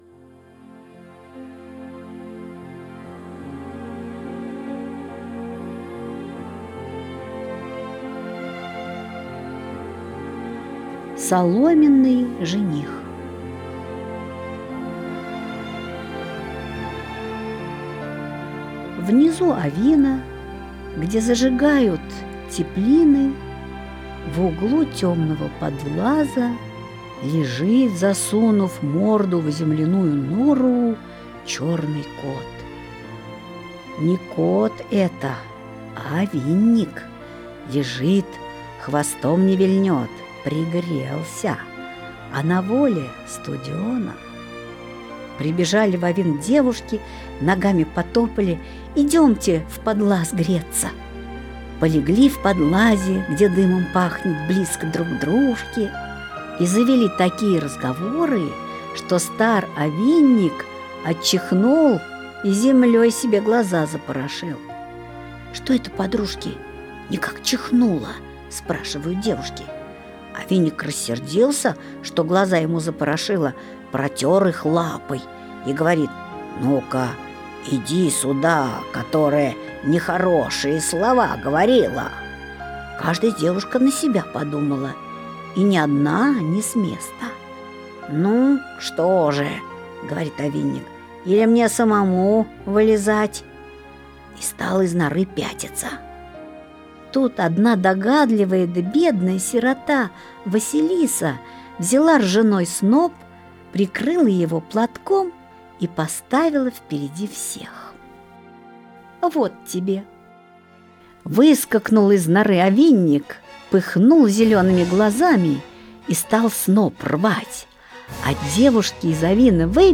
Соломенный жених - аудиосказка Алексея Толстого - слушать онлайн | Мишкины книжки